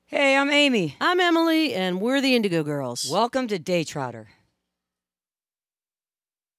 lifeblood: bootlegs: 2015-07-20: daytrotter studios - davenport, iowa
(recorded from the webcast)
01. talking with the crowd (0:06)